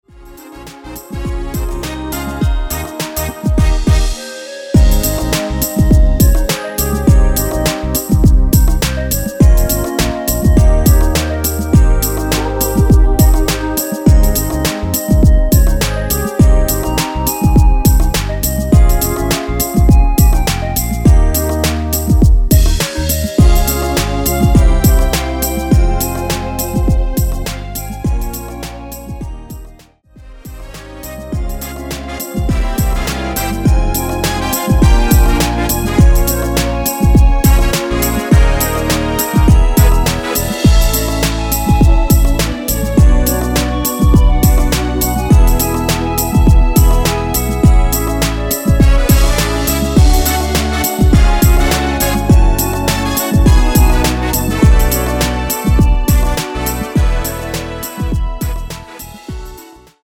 원키에서(-2)내린 멜로디 포함된 MR입니다.(미리듣기 확인)
Ab
앞부분30초, 뒷부분30초씩 편집해서 올려 드리고 있습니다.
중간에 음이 끈어지고 다시 나오는 이유는